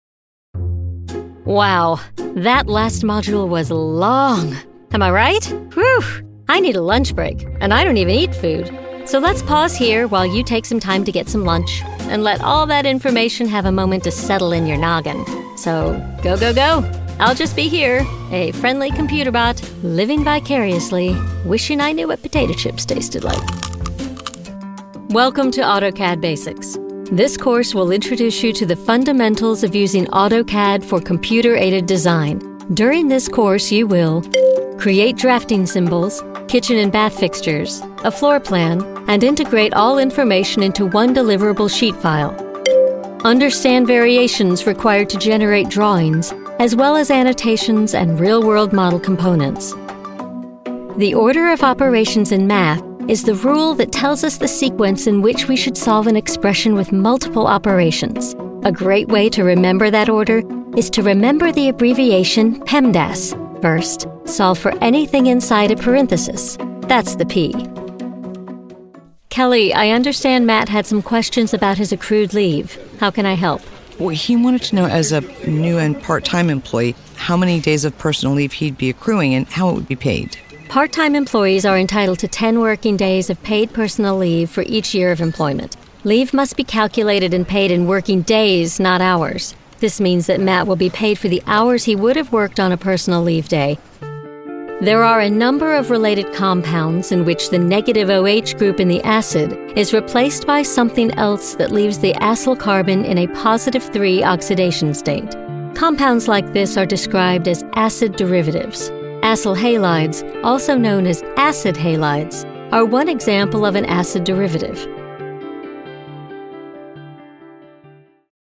Natuurlijk, Toegankelijk, Veelzijdig, Vriendelijk, Zakelijk
E-learning